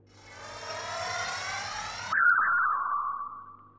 Psychic_animal_pulser_audio.ogg